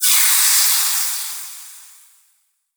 fireball_impact_magic_smoke_01.wav